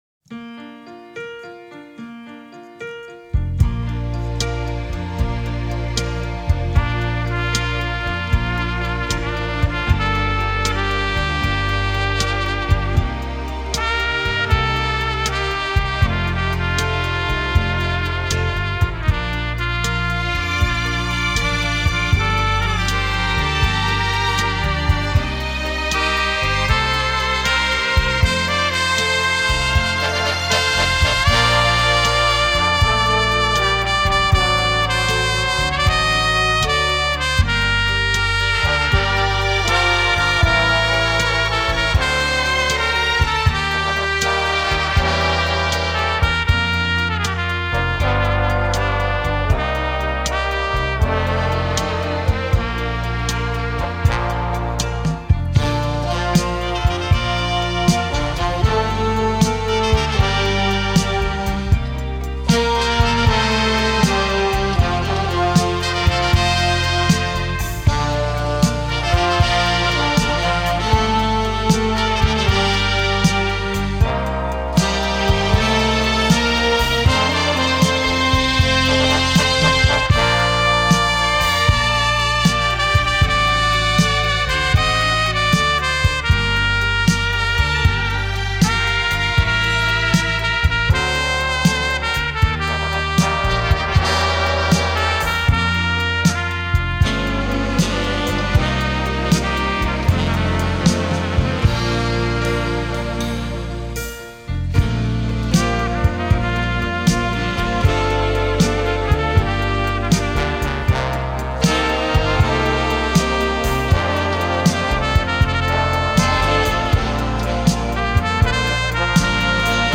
Populārā mūzika
Instrumentāls
Mūzika no kinofilmas
Instrumentāls skaņdarbs